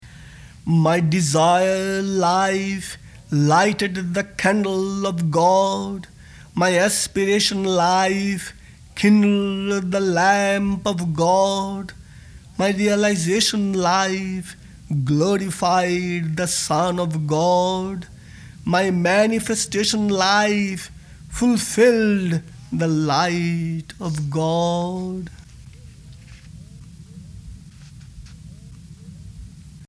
This is a recording of Sri Chinmoy reciting 28 poems from his collection of poetry, entitled ‘The Dance of Life.’ Composed in 1973, the poetry series includes 1,000 poems expressing different aspects of the spiritual path.
This recording was likely to have been made in May 1973 at Mahanir studio in Jamaica, New York.